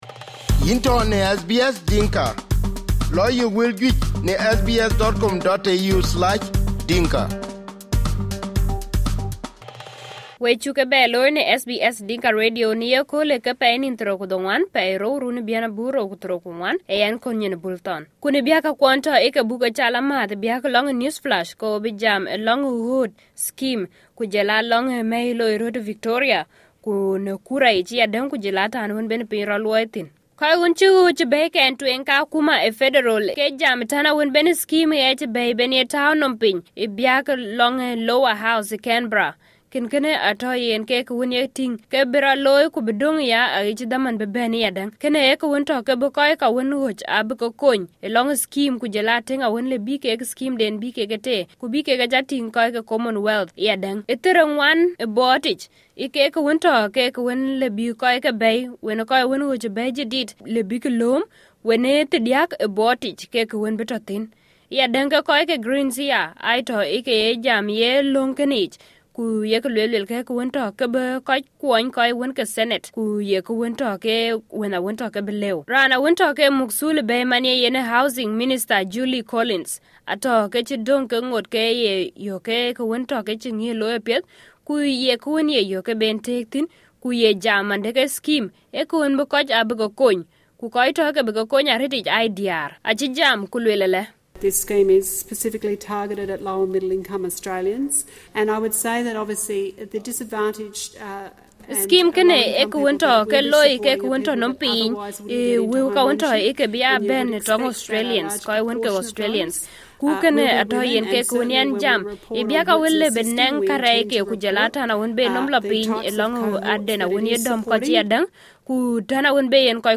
SBS Dinka News Flash 29/02/2024